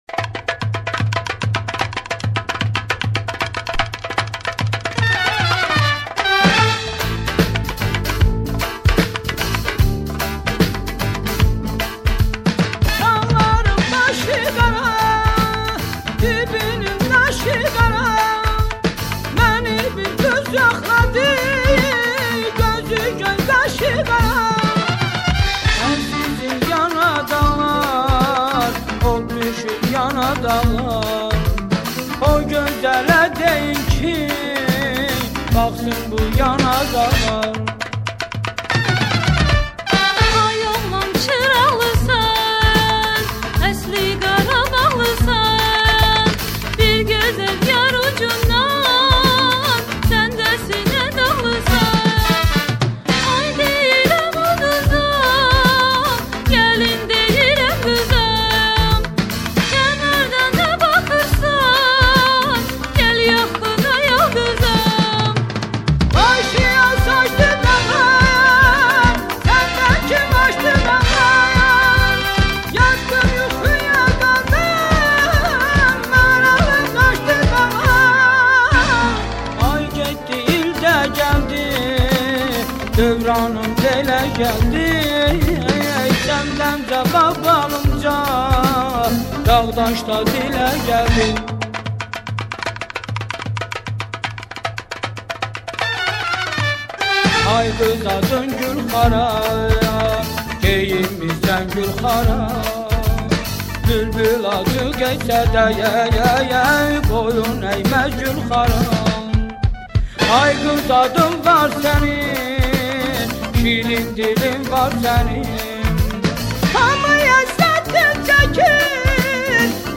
Music from Azerbaijan (Central Asia)